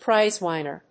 アクセント・音節príze・wìnner